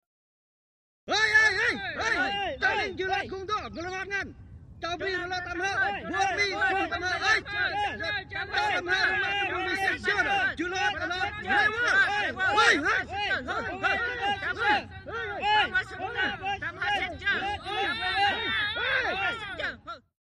Troop Activity: ( Foreign ) Vietnamese Male Group Yells. Excited Or Upset. Birds And Planes In Distant Background.